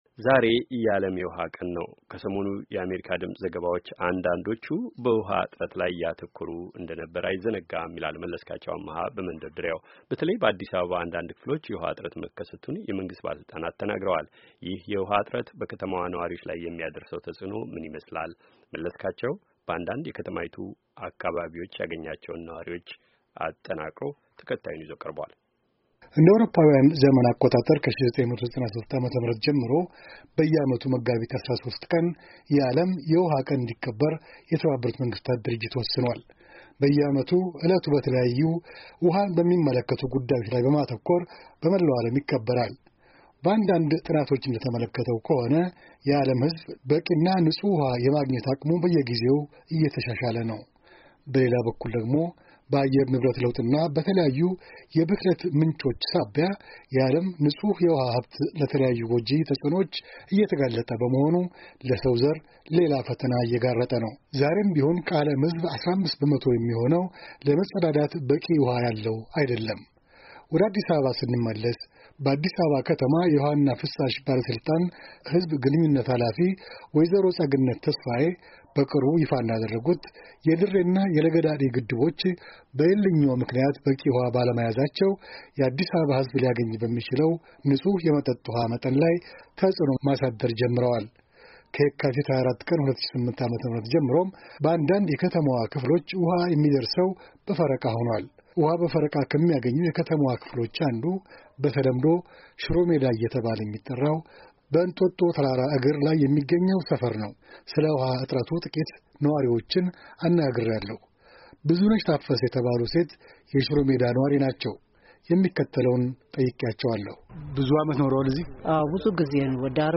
በዓለም የውሃ ቀን የአዲስ አበባ ከተማ ነዋሪዎች ድምጾች
በአዲስ አበባና አካባቢው ያለውን የውሃ እጥረት አስመልክቶ የመንግስት ባለ ሥልጣናት ያቀረቧቸውን አስተያየቶች ያካተቱ ሰሞንኛ ዘገባዎች ተከትሎ ዕለቱን ተንተርሶ የተቀናበረው የምሽቱ ዘገባ ደግሞ የከተማይቱን ነዋሪዎች ድምጽ አካቷል።